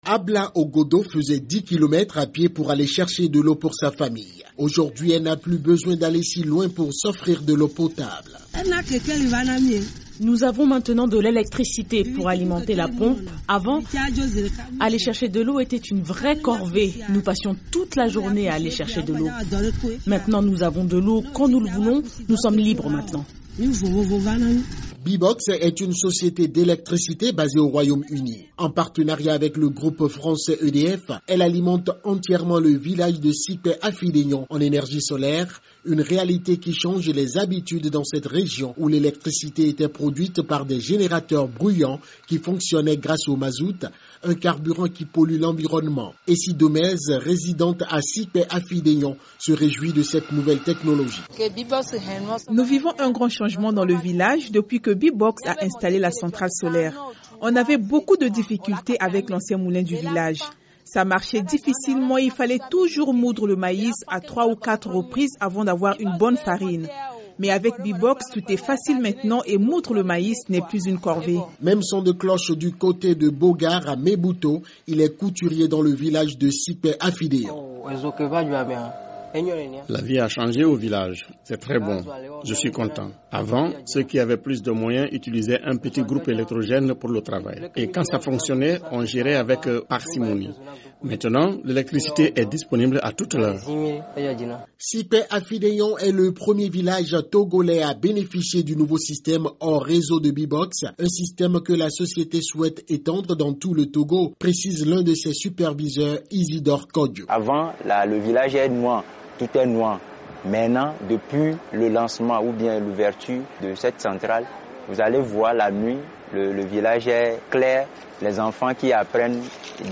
La société d'Énergie solaire BBOXX utilise la méthode d’alimentation hors réseau pour fournir de l’énergie électrique gratuite à toute une communauté au Togo, dans le village de Sikpe Afidegnon. Les habitants témoignent d’une meilleure qualité de vie et d’une augmentation de leur productivité.